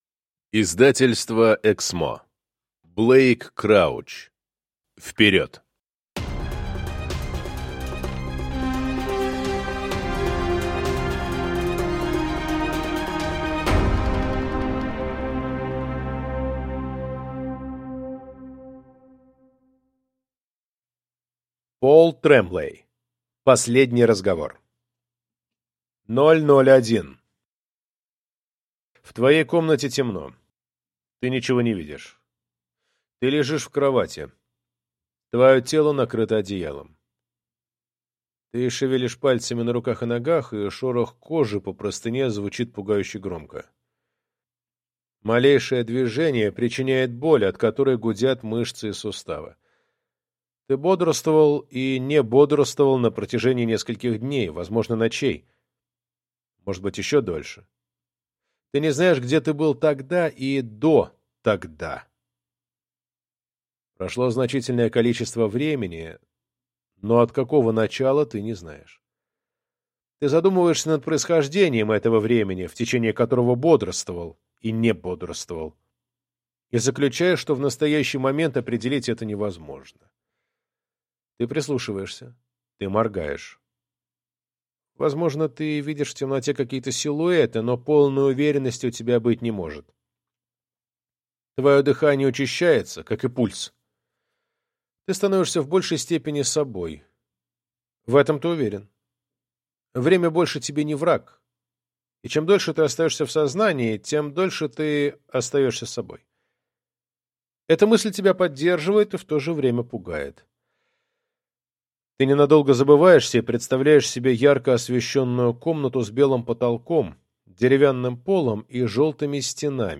Аудиокнига Вперед | Библиотека аудиокниг